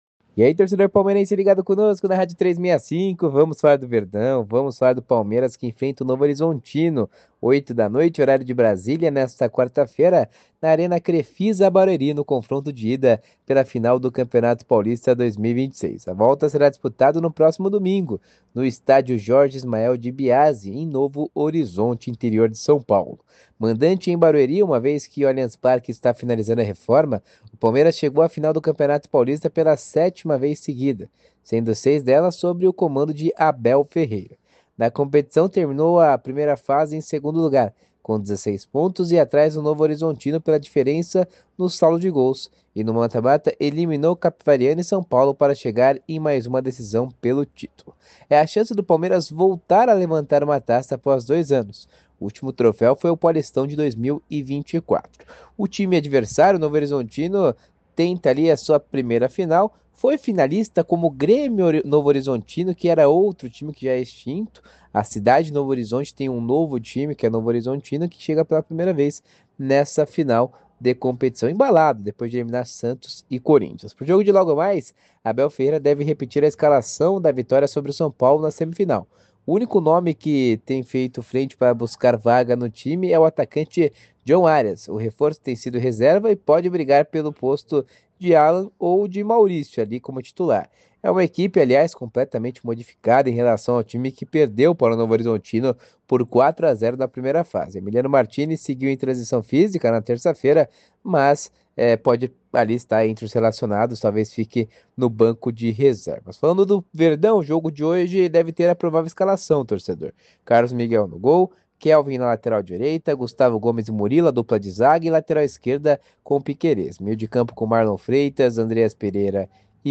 Boletin em áudio